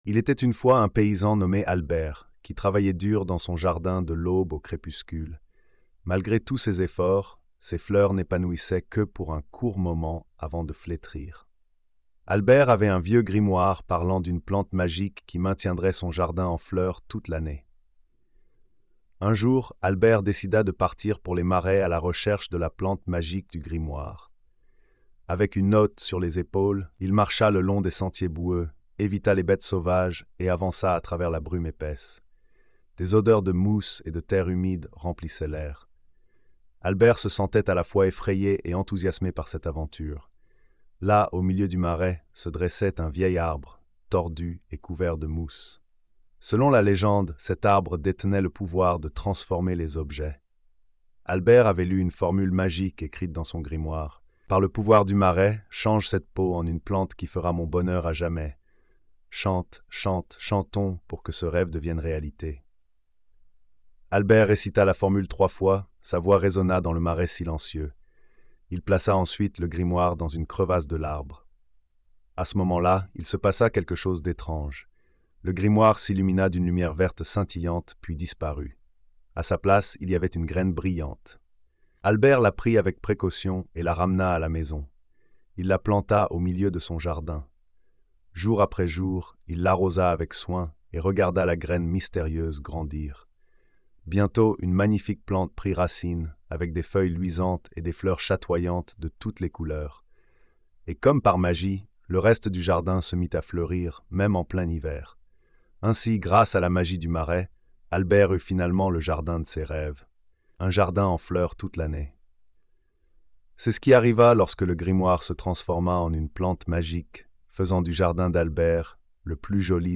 🎧 Lecture audio générée par IA